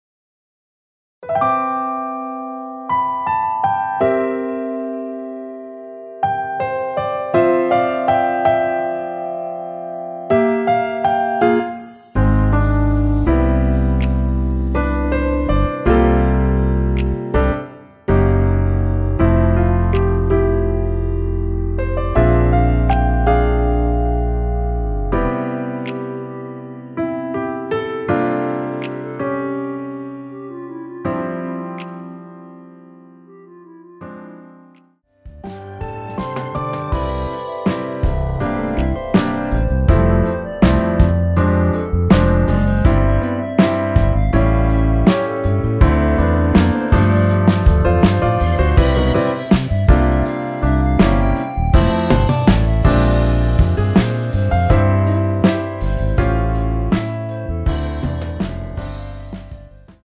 원곡(4분 56초)이 엔딩이 길고 페이드 아웃으로 끝나서
원키 멜로디 포함된 MR입니다.
앞부분30초, 뒷부분30초씩 편집해서 올려 드리고 있습니다.
중간에 음이 끈어지고 다시 나오는 이유는